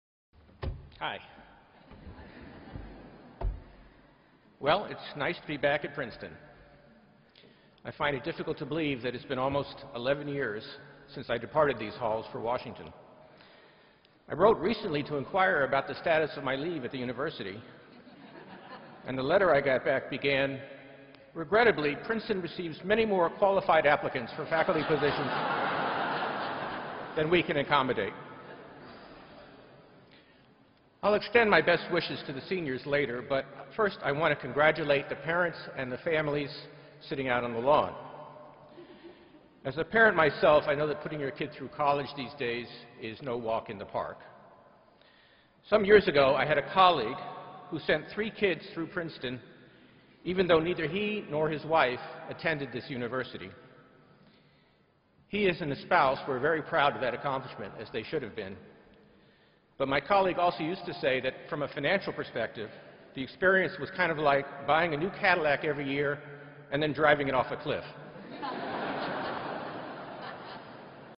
公众人物毕业演讲第297期:本伯南克2013普林斯顿(1) 听力文件下载—在线英语听力室